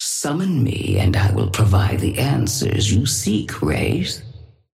Sapphire Flame voice line - Summon me and I will provide the answers you seek, Wraith.
Patron_female_ally_wraith_start_01.mp3